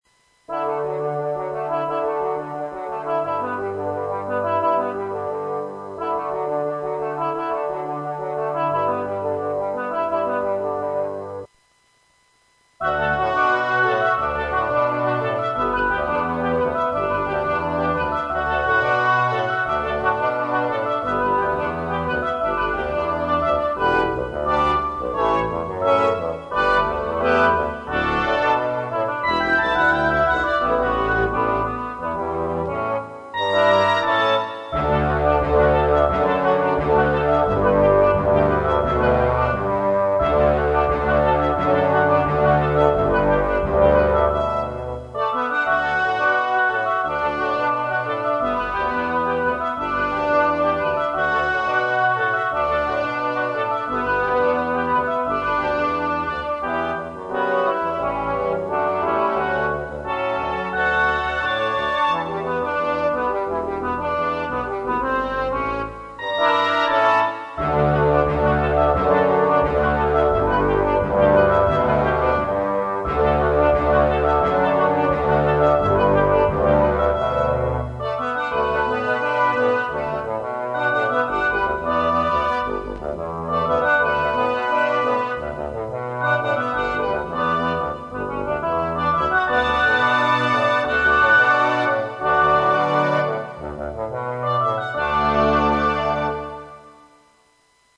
Thema wird vom Chor weitergetragen - fade out